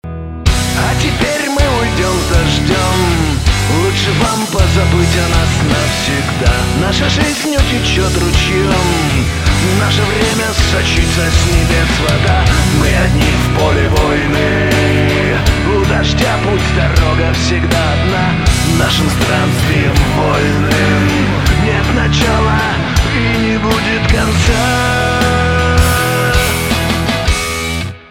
русский рок , гитара , барабаны , грустные